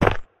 step-4.ogg.mp3